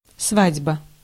Ääntäminen
IPA : /ˈwɛdɪŋ/ IPA : [ˈwɛɾɪŋ]